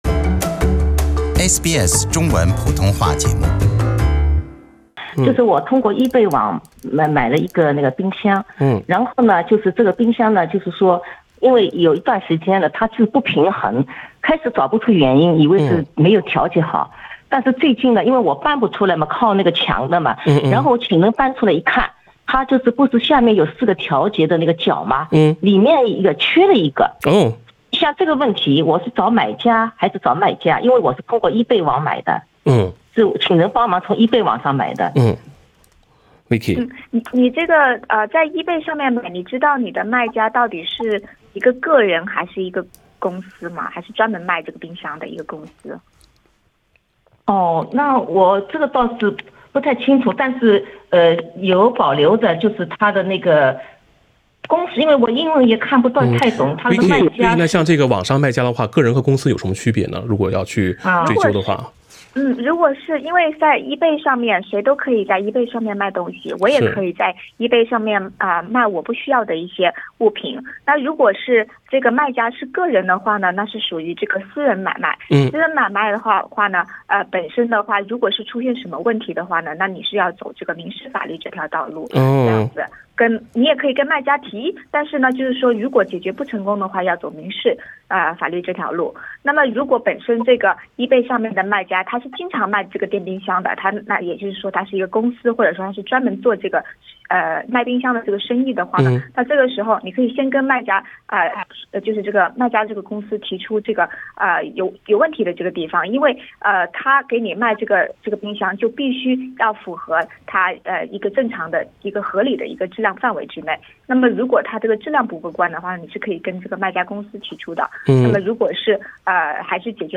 《消费者权益讲座》听众热线逢每月第二个周一上午8点30分至9点播出